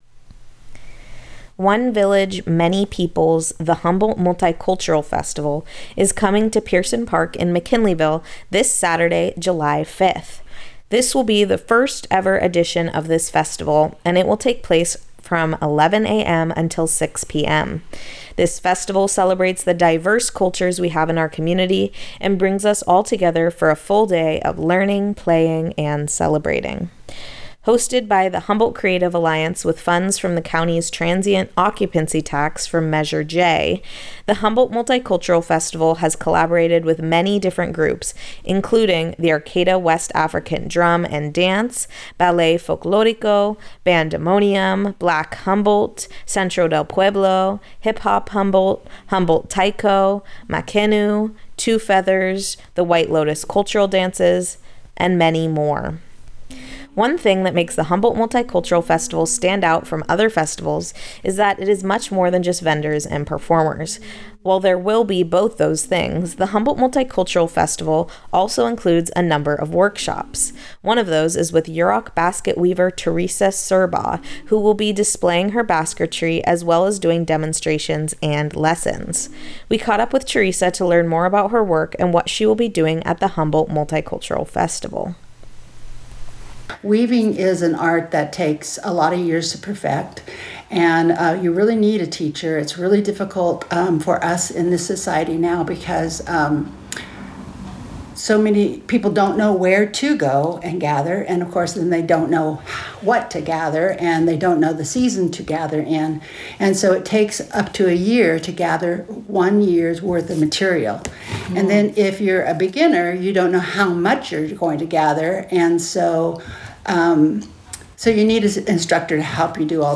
Organized by the Humboldt Creative Alliance, One Village Many Peoples: Humboldt Multi Cultural Festival comes to McKinleyville's Pierson Park on July 5th. We spoke with Yurok basket weaver